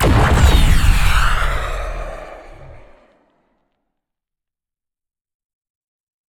missilea.ogg